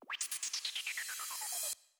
FX [Sizzle].wav